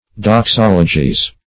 Doxology \Dox*ol"o*gy\, n.; pl. Doxologies.